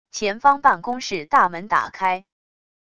前方办公室大门打开wav音频